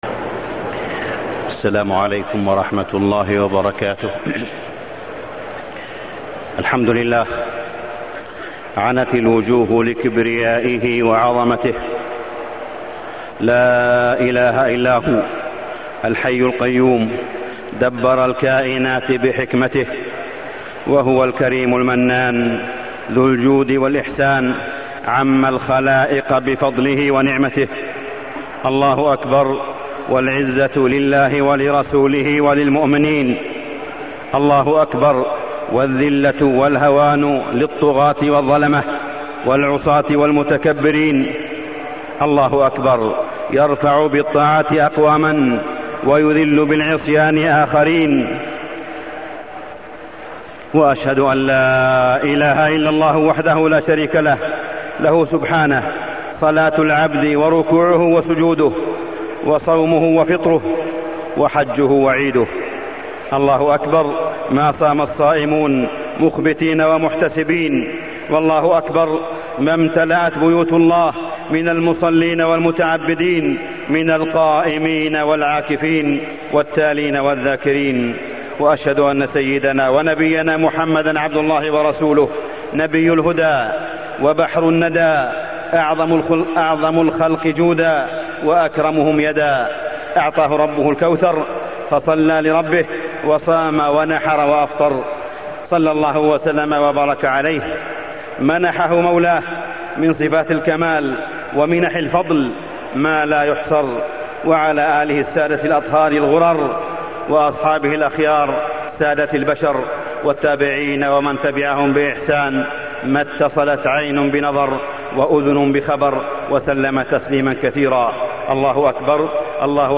خطبة عيد الفطر
تاريخ النشر ١ شوال ١٤٢٥ هـ المكان: المسجد الحرام الشيخ: معالي الشيخ أ.د. صالح بن عبدالله بن حميد معالي الشيخ أ.د. صالح بن عبدالله بن حميد خطبة عيد الفطر